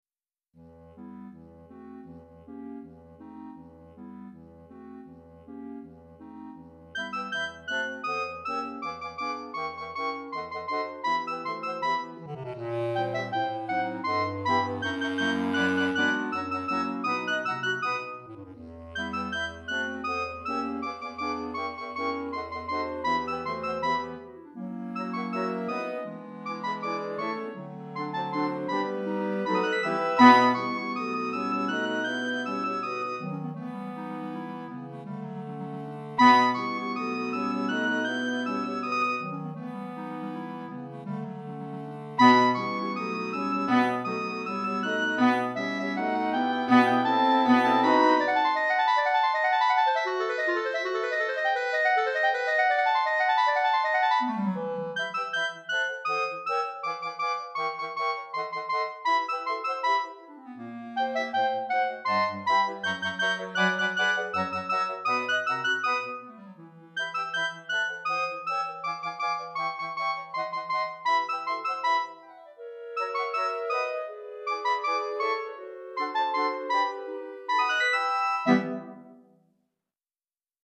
Petite Clarinette Mib 5 Clarinettes en Sib 1 Clari